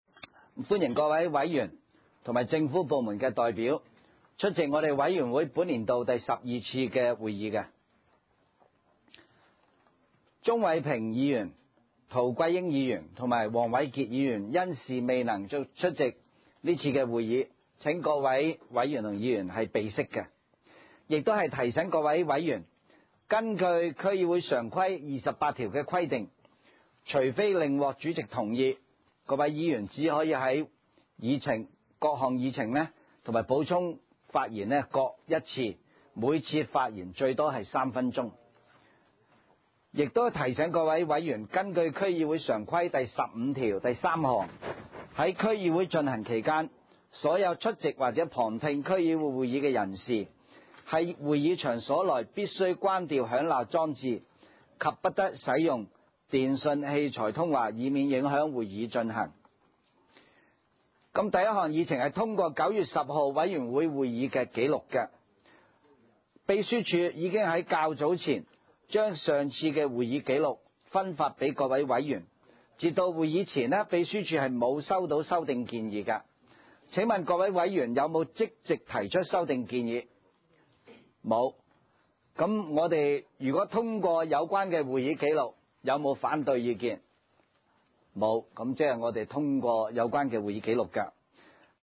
文娛康樂及體育委員會第十二次會議
荃灣民政事務處會議廳